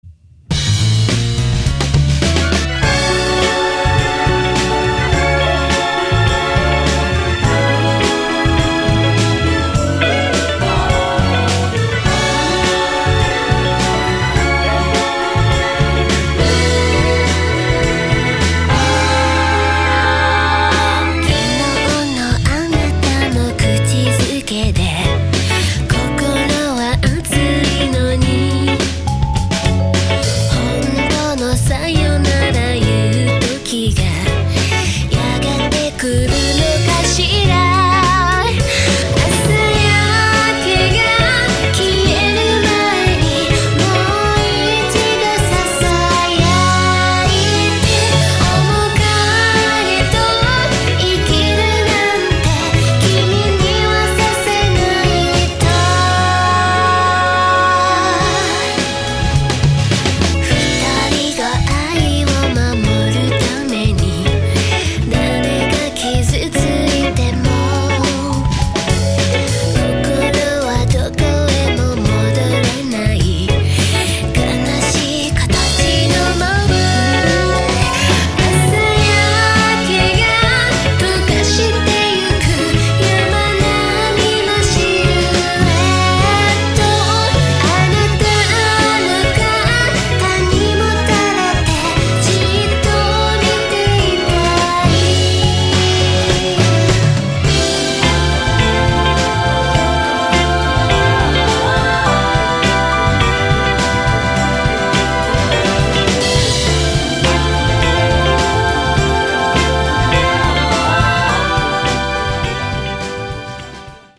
ジャンル(スタイル) JAPANESE POP / CITY POP